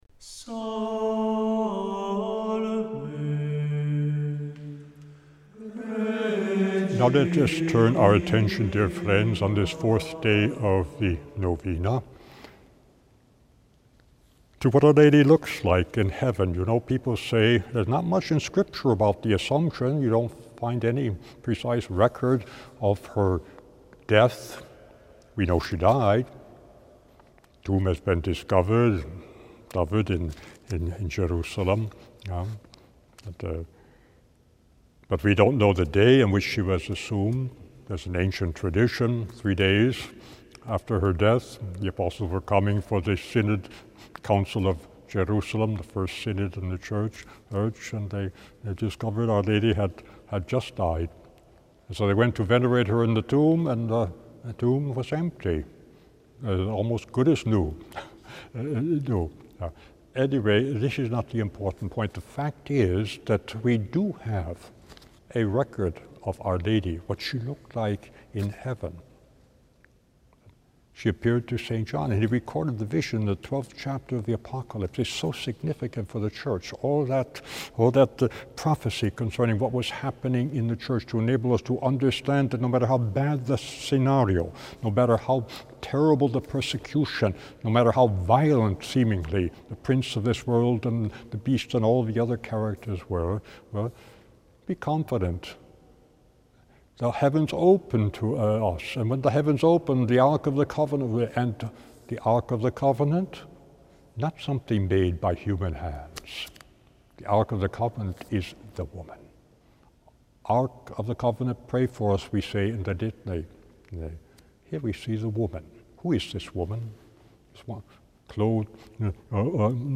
Each day, from the 6th up to the 14th, he gives a short reflection on Our Lady followed by a prayer.